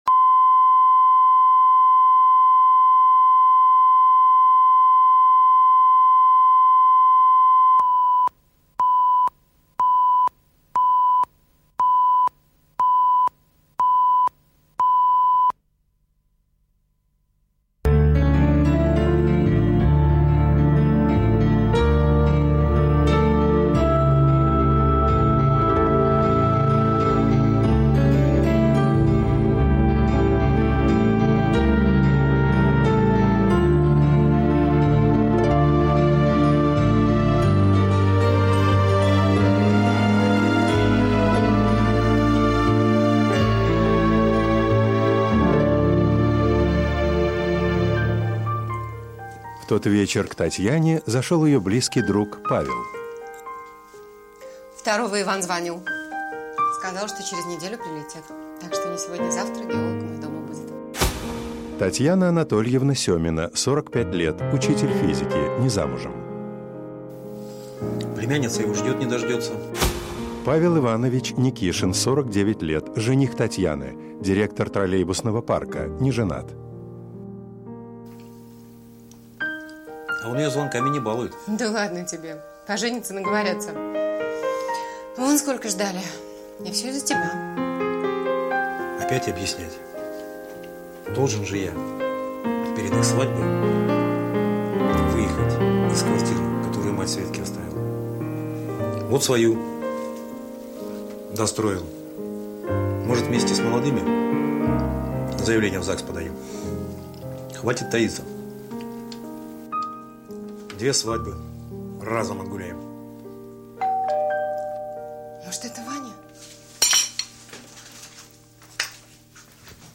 Aудиокнига Невестка с севера Автор Александр Левин.